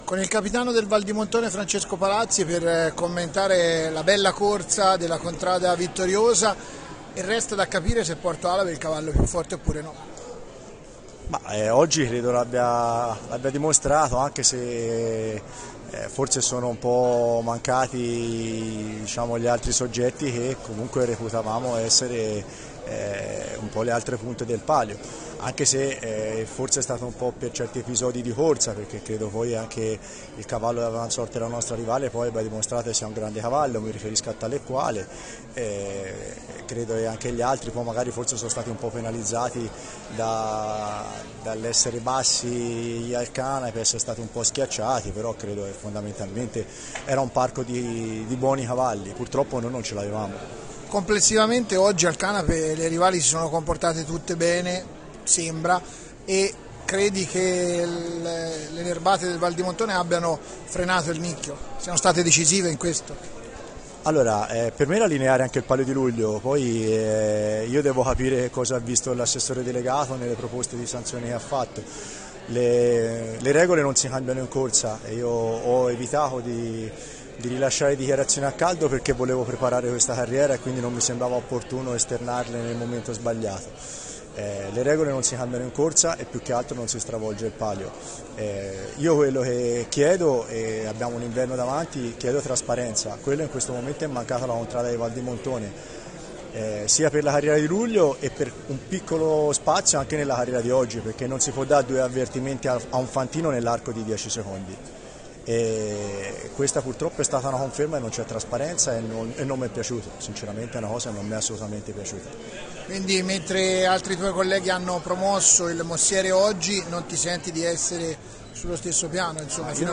Interviste
Come di consueto, dopo la carriera, abbiamo raccolto i commenti dei capitani delle contrade che hanno partecipato al palio del 16 agosto.